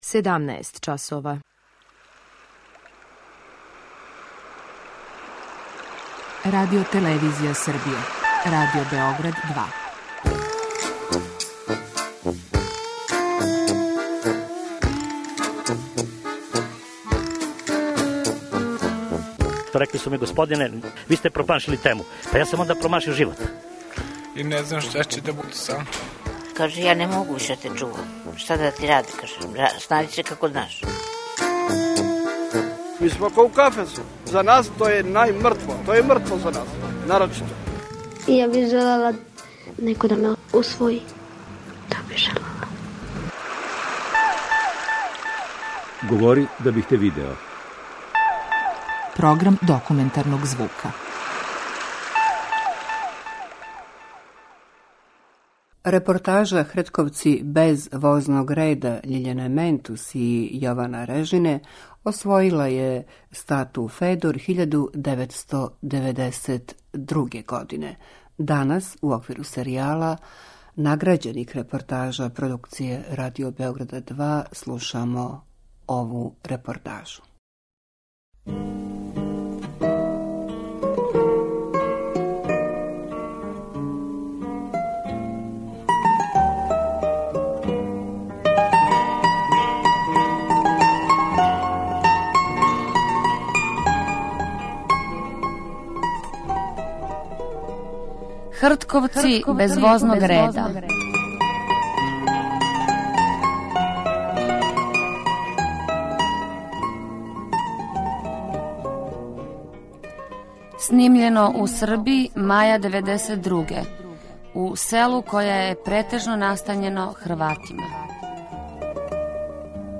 Документарни програм: Серијал награђених репортажа
Ова оригинална продукција Радио Београда 2 сједињује квалитете актуелног друштвеног ангажмана и култивисане радиофонске обраде.